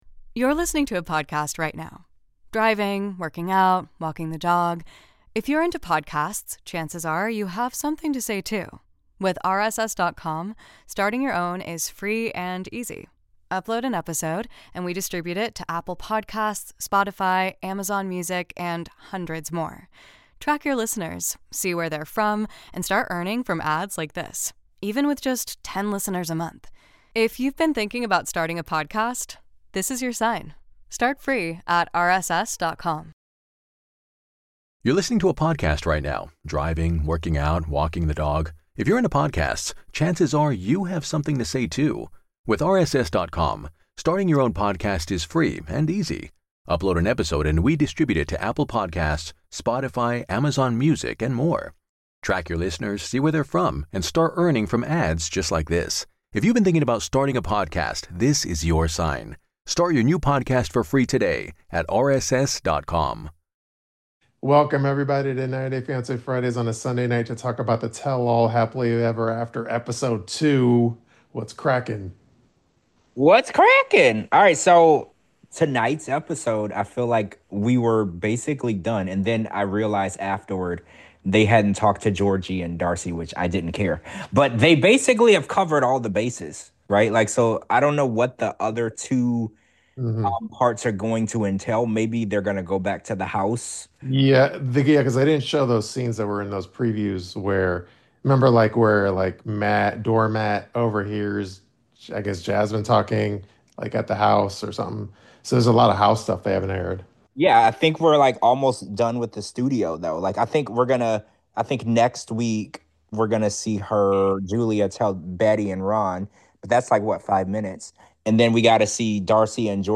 live podcast is unfiltered and unpredictable.